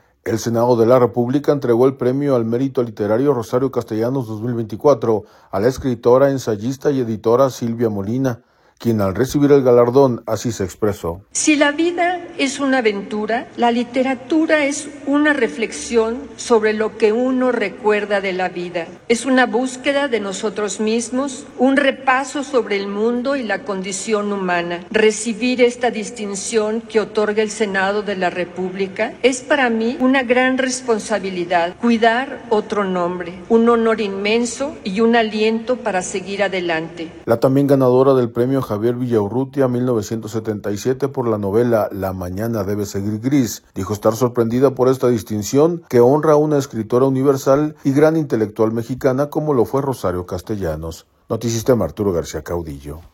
audio El Senado de la República entregó el Premio al mérito literario Rosario Castellanos 2024 a la escritora, ensayista y editora Silvia Molina, quien recibir el galardón, así se expresó.